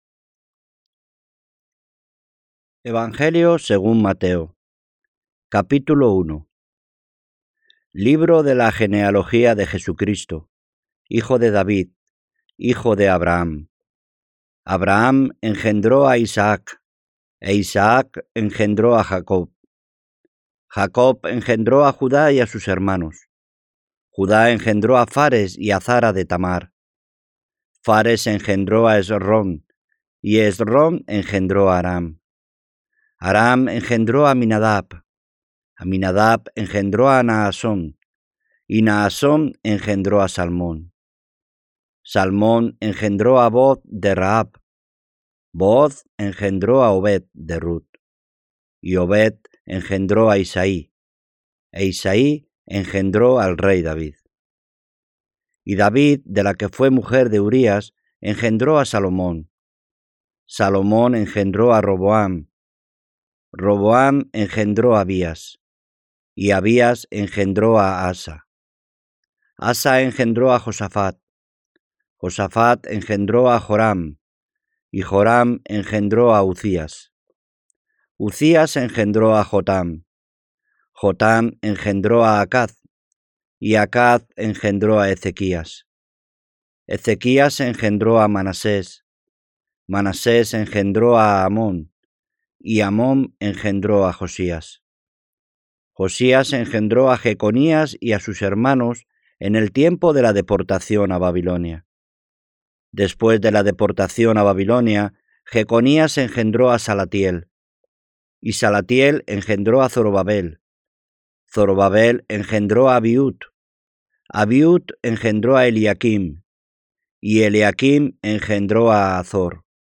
Escuchar el Nuevo Testamento (Moderna 1929, actualizada 2020) con lectura sincronizada, ayuda a leer y a memorizar la Palabra de Dios.